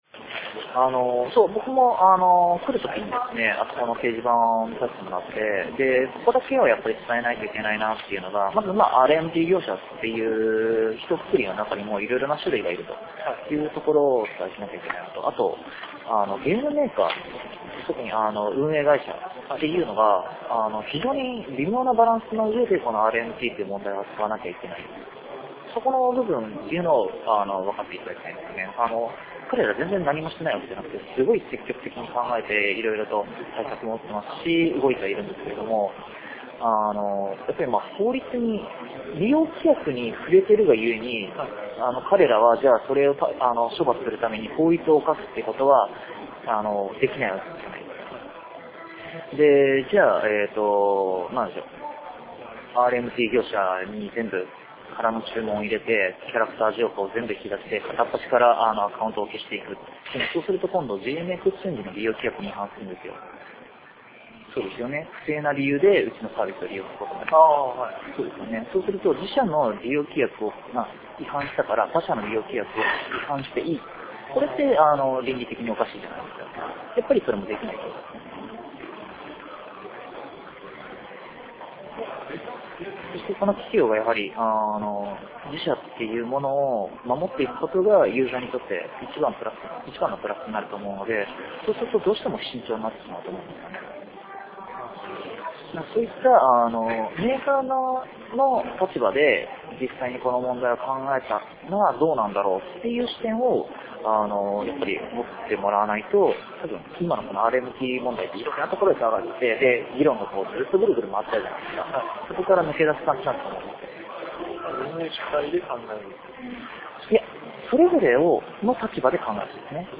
MMOに関わる様々な人との対談、インタビューなどをそのまま保存しています。
生の状況をそのまま感じれるよう、録音時の会話をそのままアップしている時もあります。